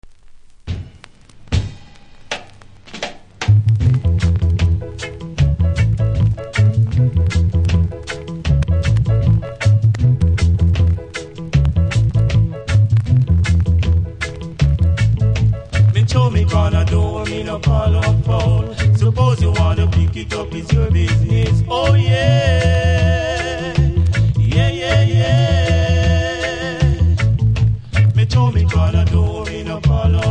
多少うすキズありますが音は良好なので試聴で確認下さい。